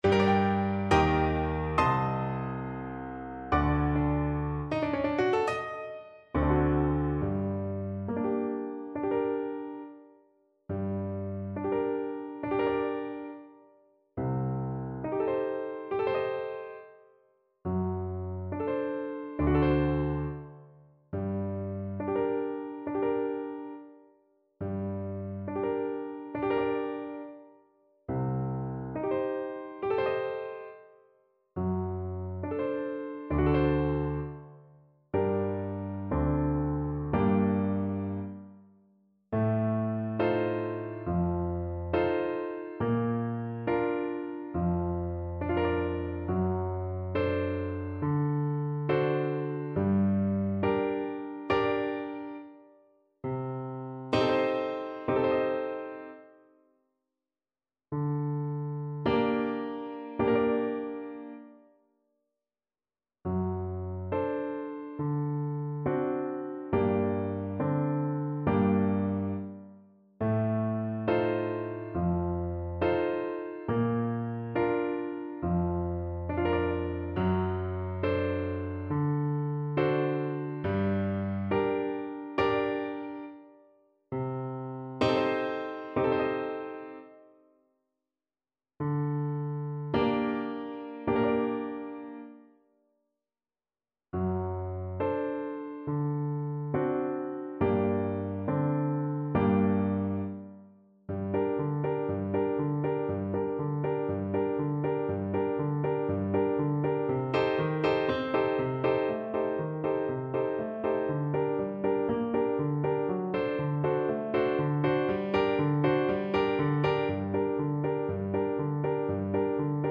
Flute
G minor (Sounding Pitch) (View more G minor Music for Flute )
2/4 (View more 2/4 Music)
Largo = c.69
Classical (View more Classical Flute Music)
Gypsy music for flute